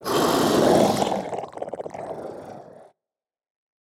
KS_Beast_1.wav